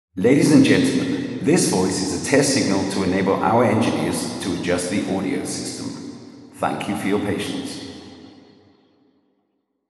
Um die Simulationen im Vorfeld „hörbar“ zu machen, haben wir zusätzlich eine Auralisation erstellt, die den Entscheidern einen realistischen Höreindruck von der Beschallungsanlage vermittelte.
Altarraum
Altarraum.wav